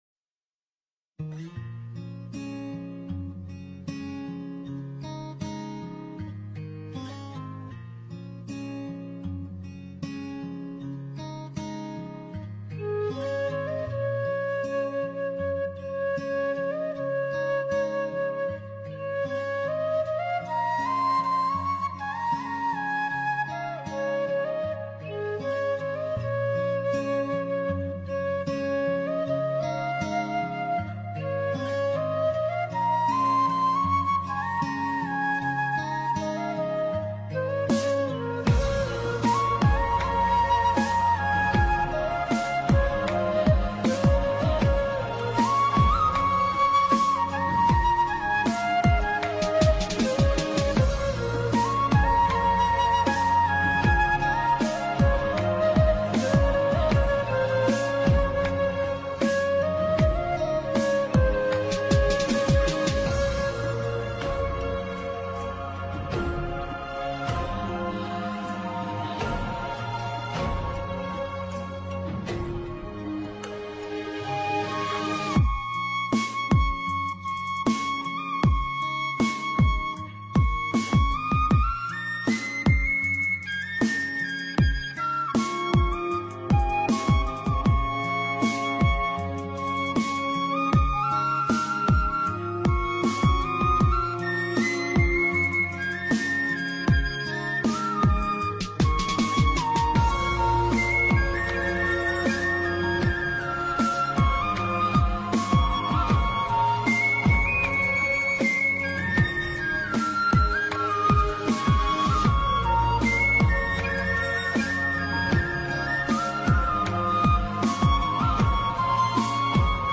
giai điệu trẻ trung và trong trẻo.
bản nhạc không lời